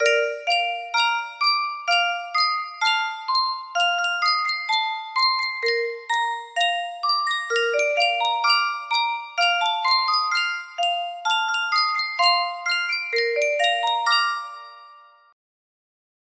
Sankyo Music Box - Hush Little Baby 5L music box melody
Full range 60